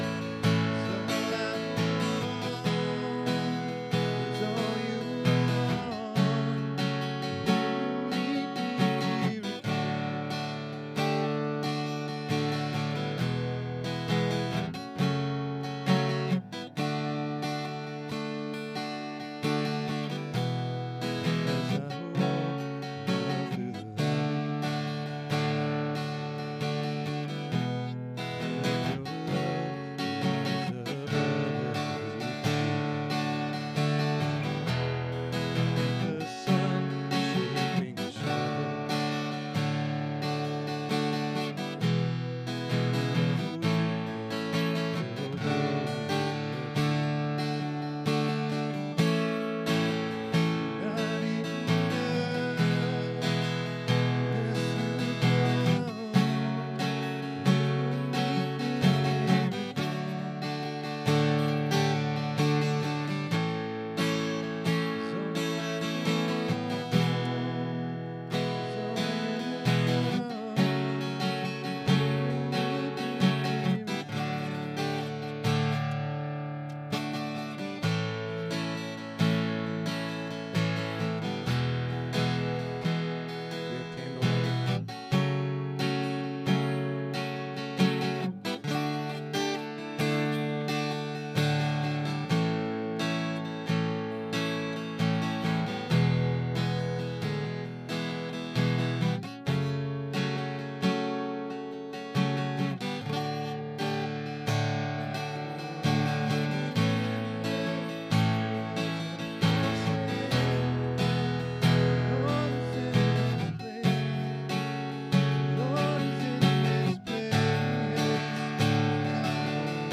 Download Download Reference Acts 9:19b-31, John 3:21 Sermon Notes Click Here for Notes 250323.pdf SERMON DESCRIPTION After Sauls encounter with Jesus, he gained a greater appreciation for grace.